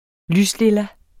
Udtale [ ˈlys- ]